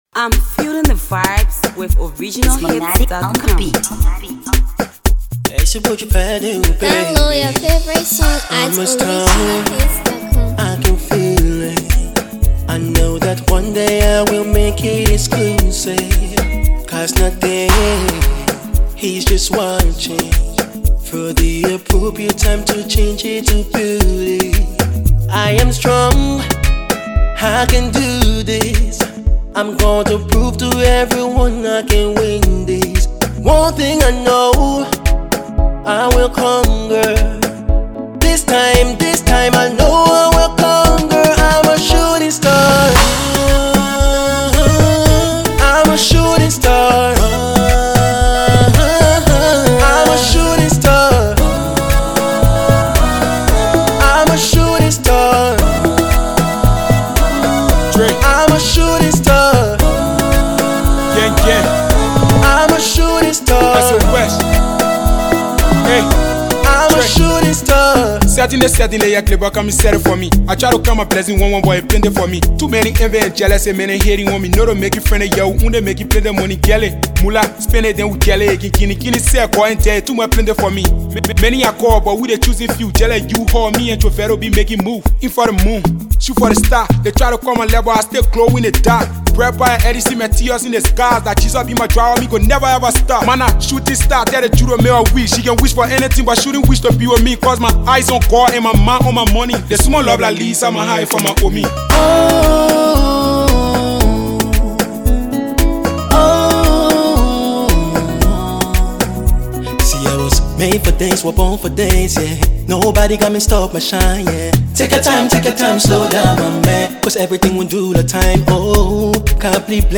Liberian vocalist